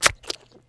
splash1.wav